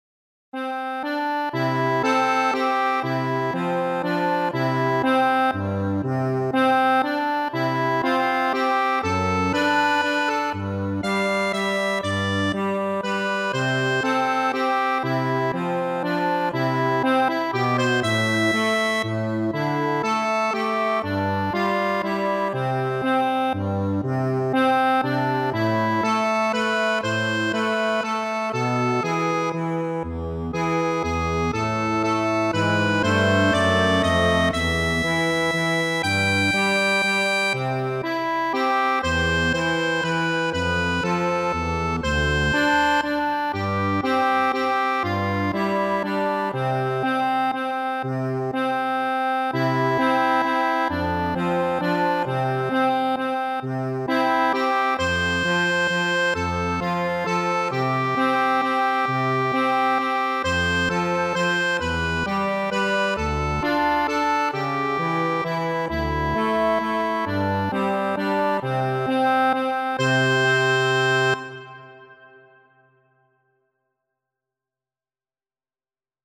Free Sheet music for Accordion
3/4 (View more 3/4 Music)
C major (Sounding Pitch) (View more C major Music for Accordion )
Slow one in a bar .=c.40
Accordion  (View more Intermediate Accordion Music)
vieni_sul_mar_ACC.mp3